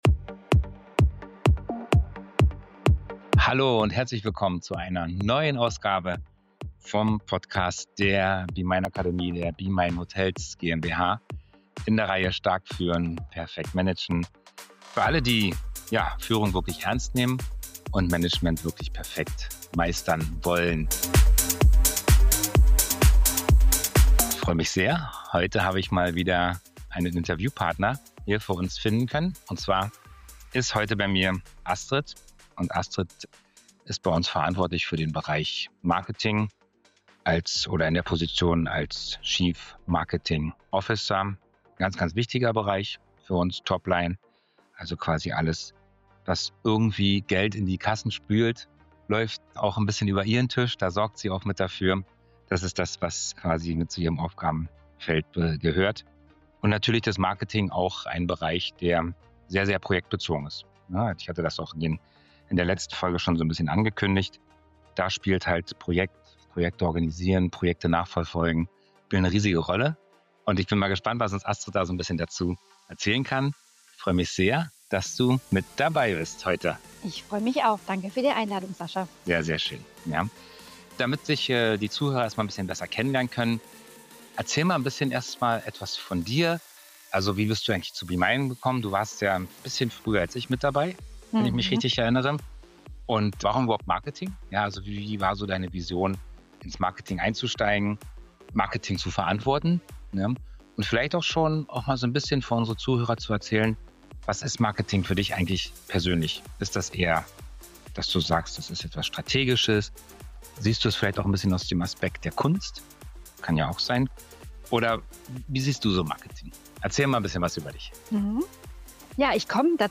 Wir sprechen über die Besonderheiten von Führung unter Projektdruck, über Motivation in intensiven Phasen, den Ausgleich zwischen Beruf und Privatleben und darüber, welche Führungsprinzipien ihr helfen, ein kleines Team effektiv zu leiten und gleichzeitig kreativ zu bleiben. Ein ehrliches, inspirierendes Gespräch über Leadership im Marketing, Organisationstalent und den Mut, Führung menschlich und klar zugleich zu leben.